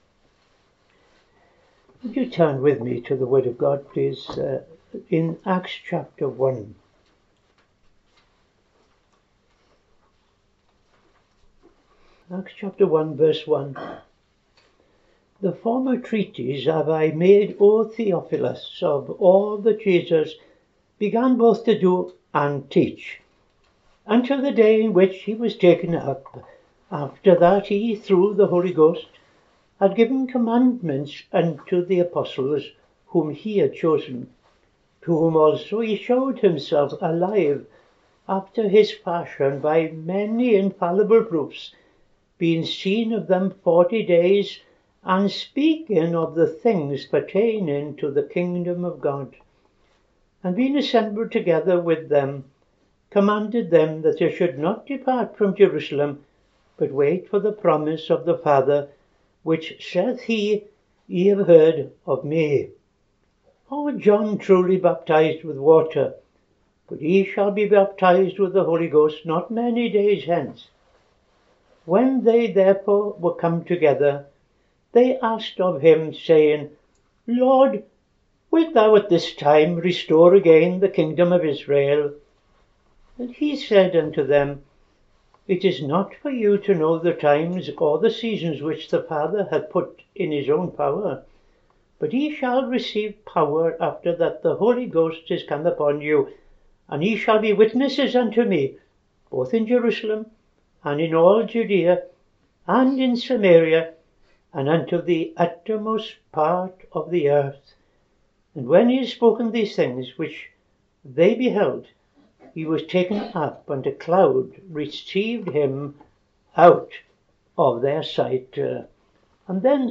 Reading Acts 1:1-9; II Timothy 4:16-18